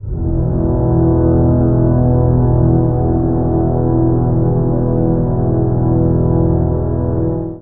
55aa-orc01-c#1.wav